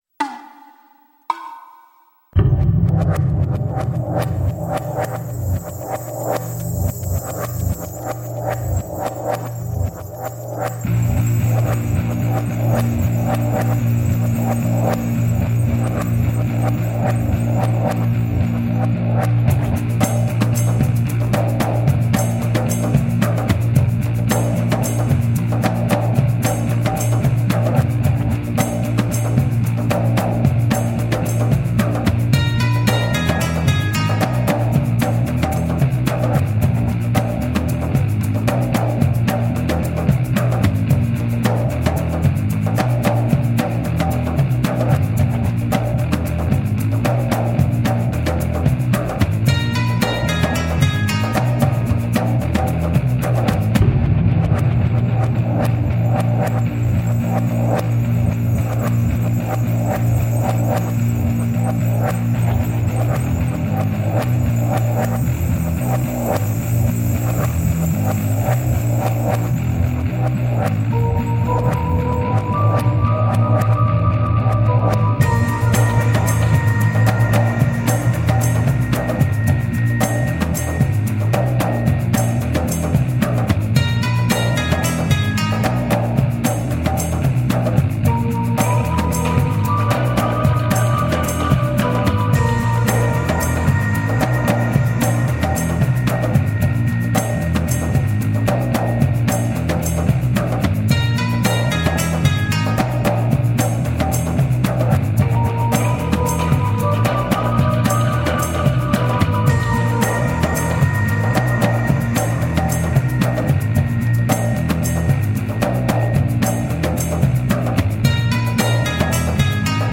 Passionate eastern percussion.